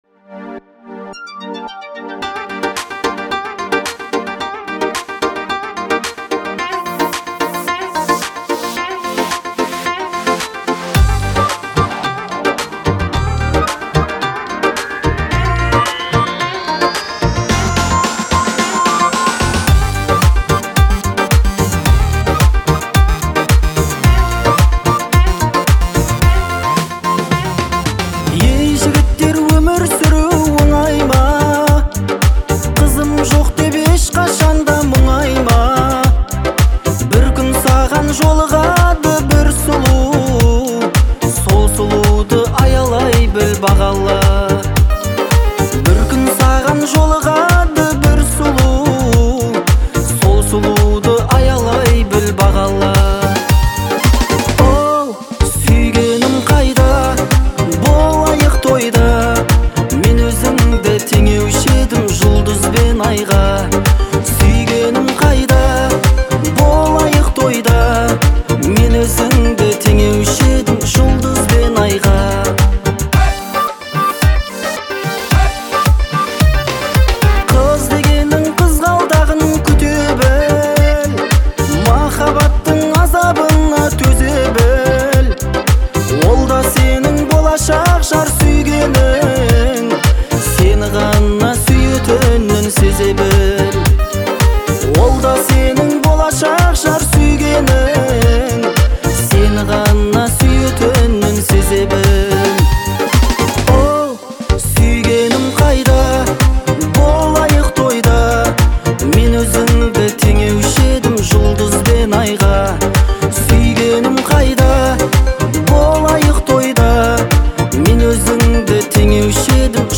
Звучание песни гармонично сочетает нежный вокал